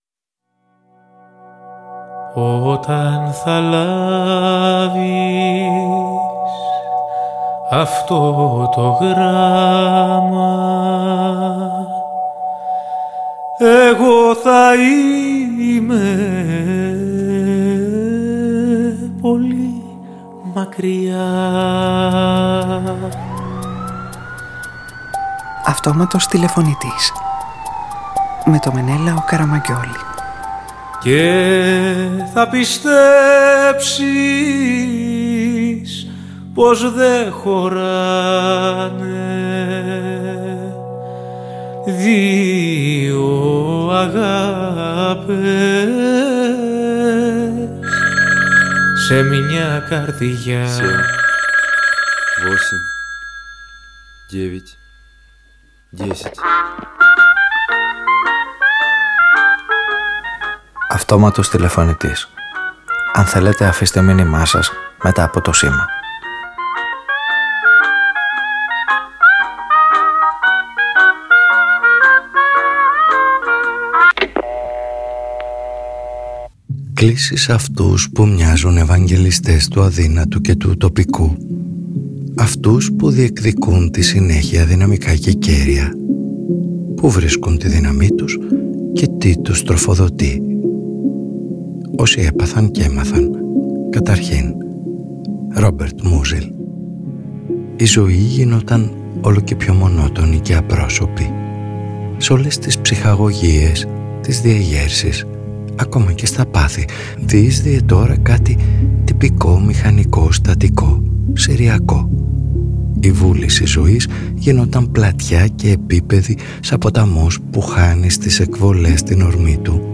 Οι ήρωες αυτής της ραδιοφωνικής ταινίας διεκδικούν το δικαίωμα στη βλακεία για να μπορέσουν να συνεχίσουν χωρίς αναστολές, τύψεις, φόβο και περιττές μεταμέλειες και στήνουν νέες παραβολές για όσους έχουν ανάγκη να πιστεύουν με τη συνέργεια των Ρ. Μούζιλ, Λ. Βιττγκενστάιν, Κ. Κράους, Α. Καμύ, Μ. Φουκώ, Ιονέσκο, Μάρκου Αυρήλιου, Ε. Σιοράν και Σ. Τσακνιά.